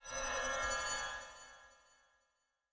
Uncanny Sound
神秘的声音